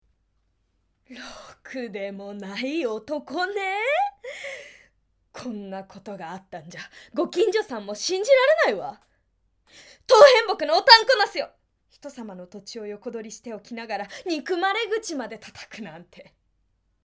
罵る女性
ボイスサンプル